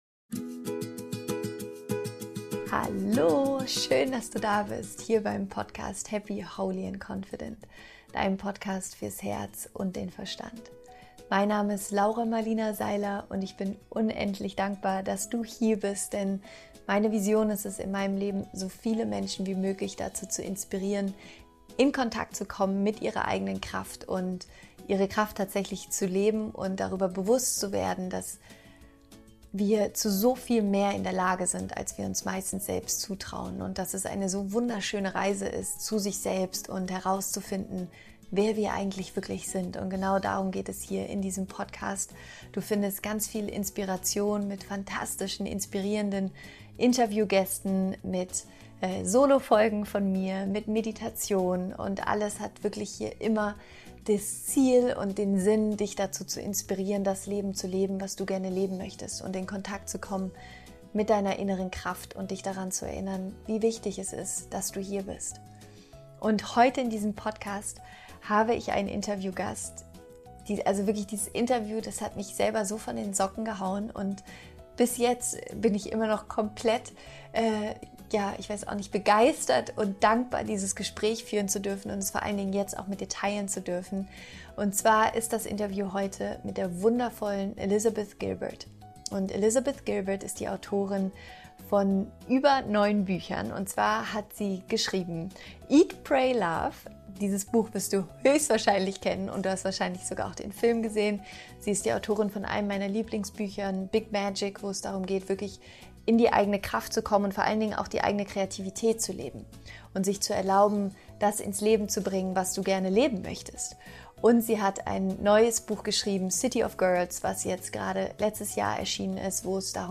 Du bist der wichtigste Mensch in deinem Leben – Interview Special mit Elizabeth Gilbert